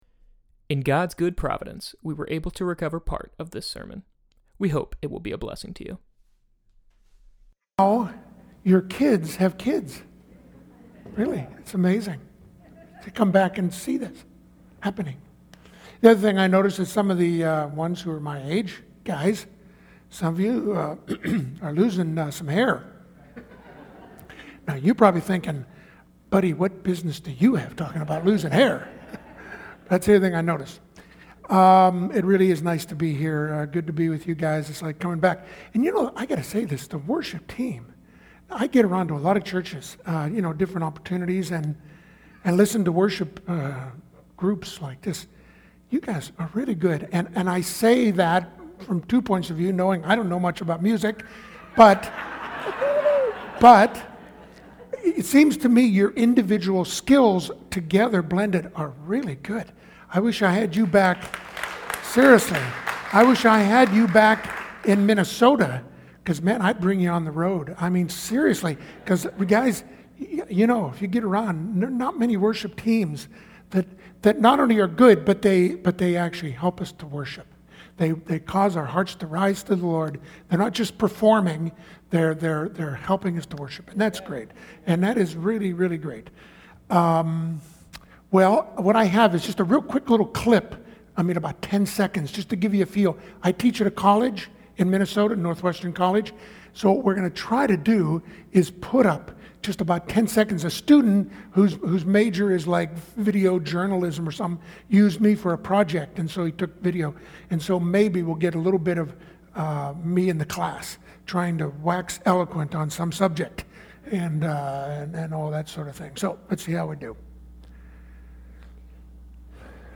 Mission Sunday